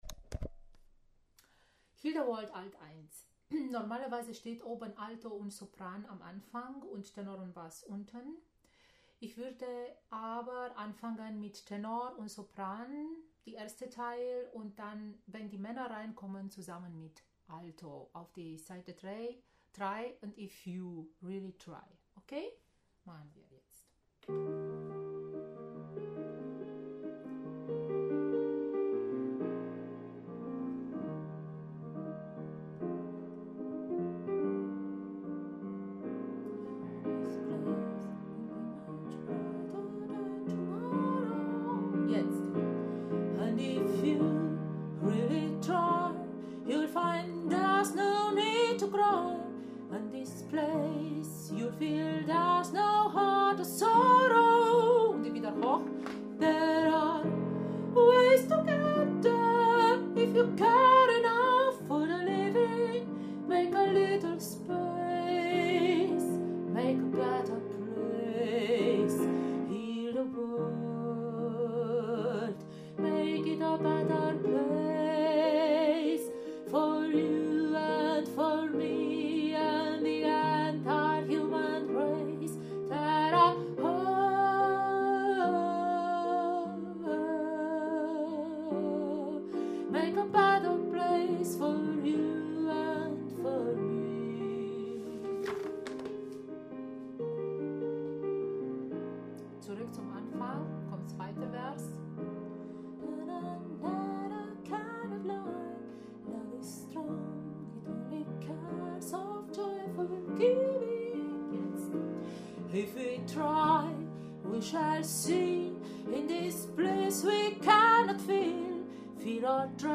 Heal-the-World-Alto1.mp3